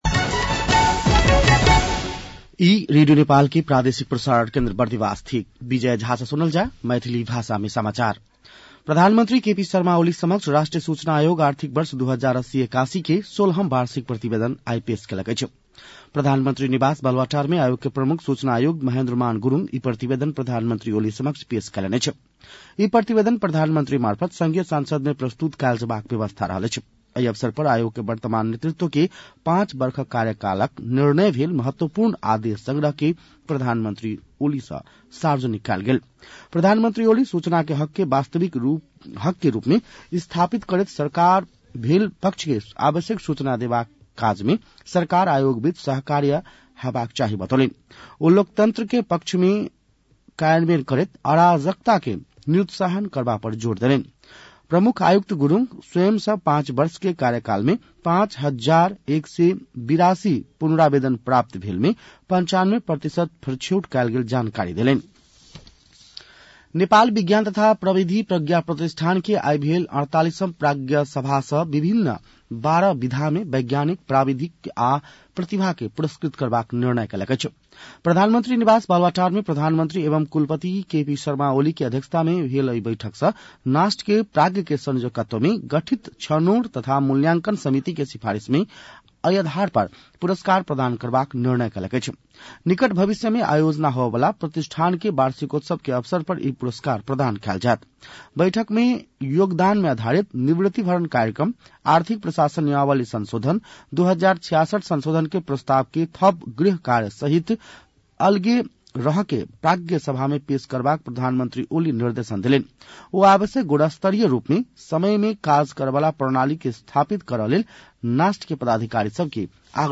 मैथिली भाषामा समाचार : १४ मंसिर , २०८१
Maithali-news-8-13.mp3